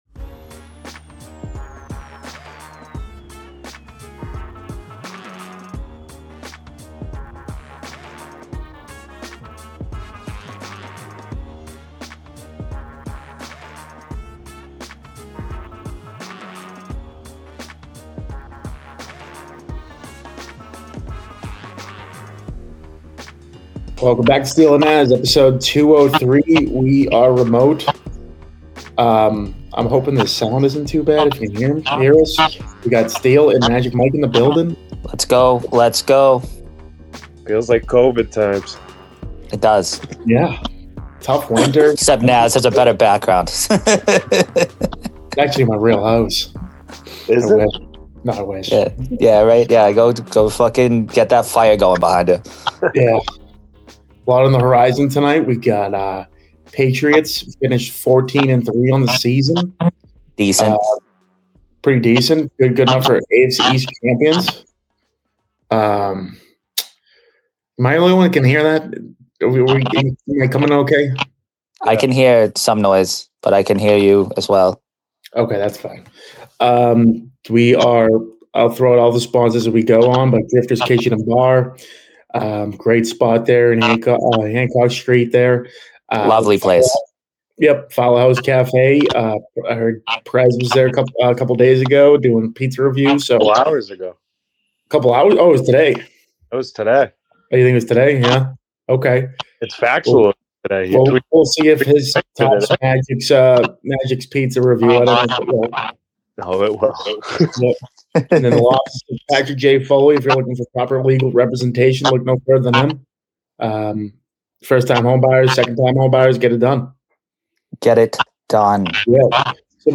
ran a no-guest REMOTE show